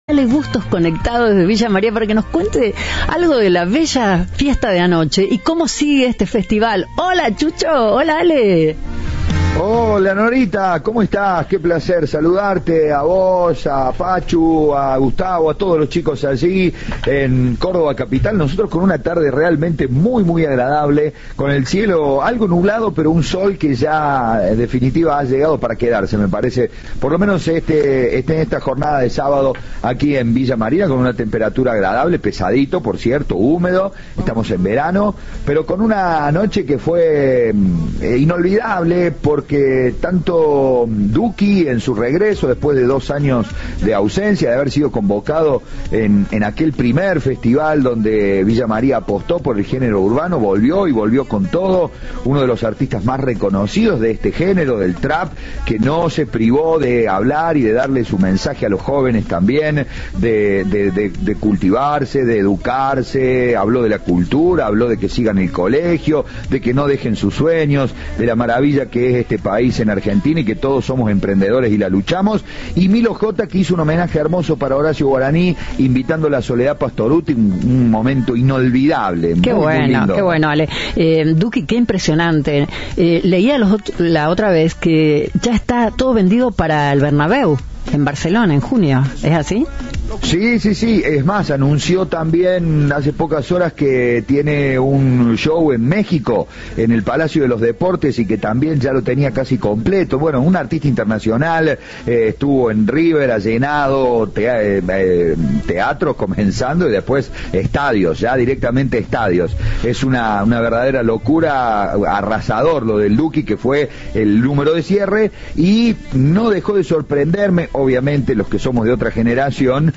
Audio. Eduardo Acastello, intendente de Villa María: "Fue una primera noche fantástica"
En diálogo con Cadena 3, el intendente de Villa María, Eduardo Acastello, expresó estar muy feliz por el éxito de la primera noche del Festival, que tuvo una ocupación del 90%.